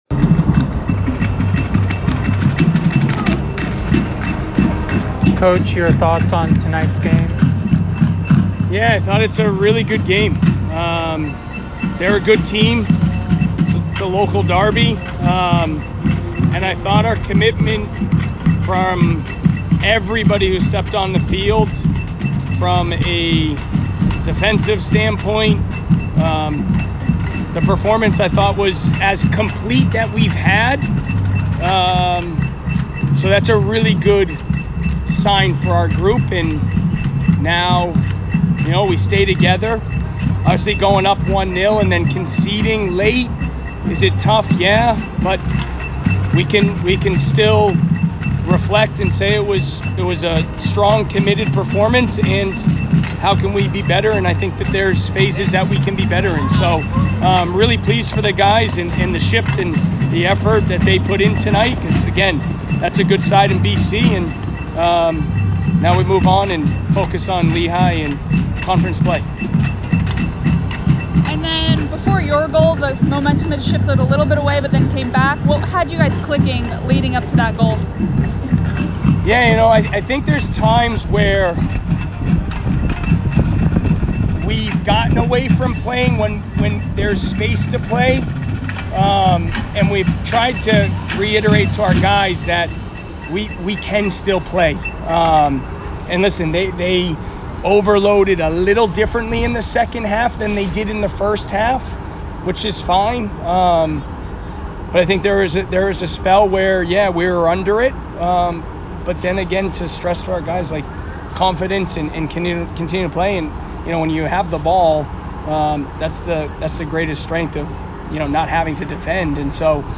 Boston College Postgame Interview